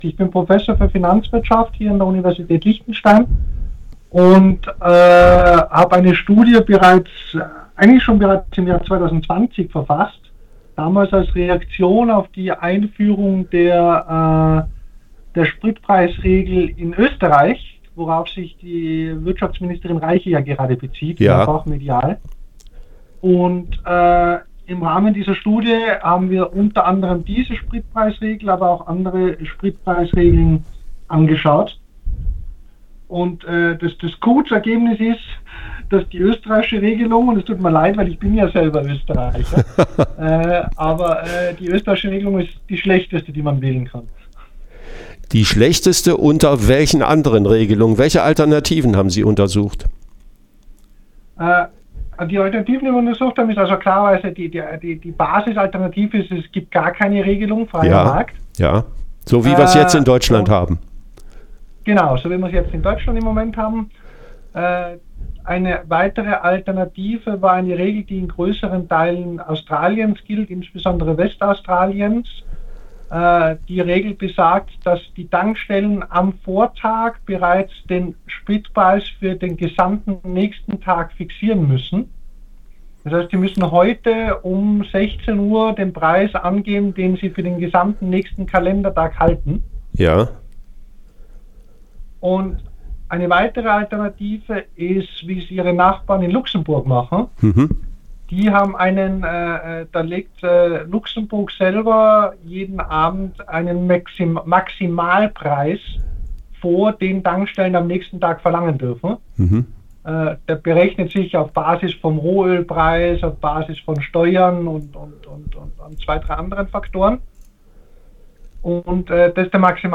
Modelle der Spritpreisbremse, welche verbraucherfreundlich sind und welche nicht - Ein Interview